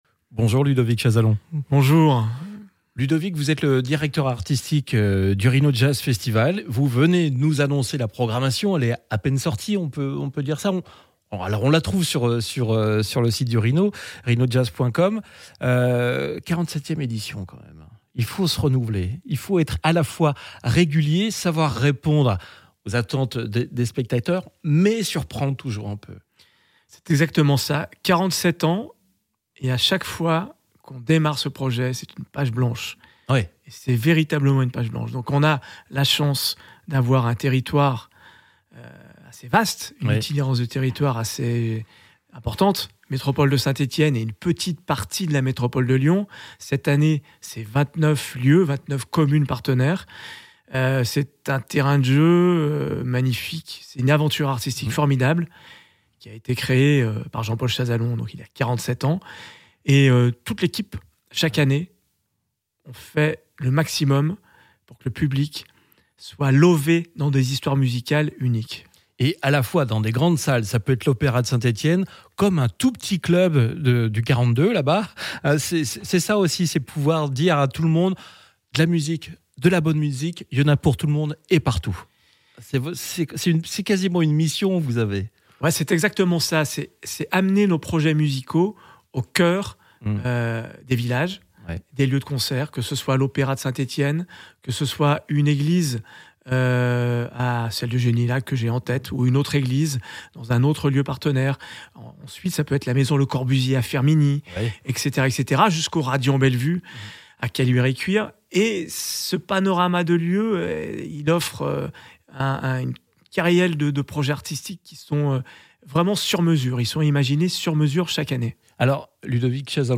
Interview Jazz Radio.